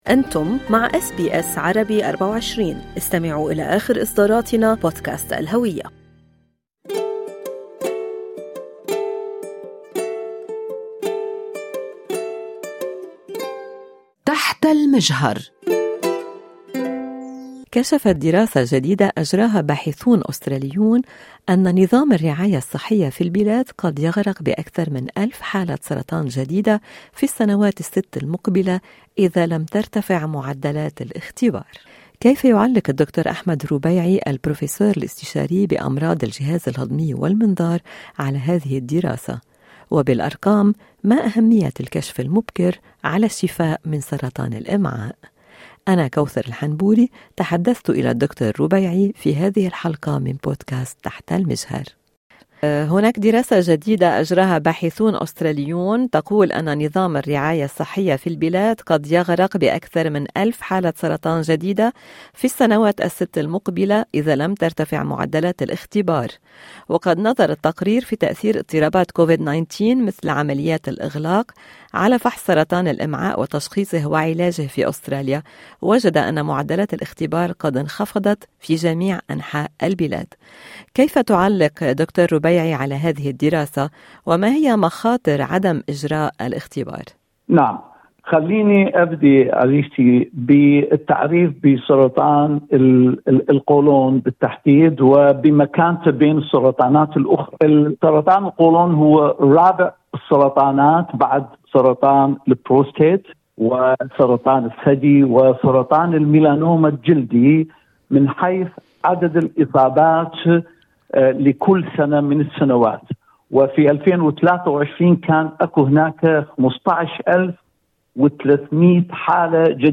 هل بالإمكان تجنب سرطان الأمعاء؟ طبيب يشرح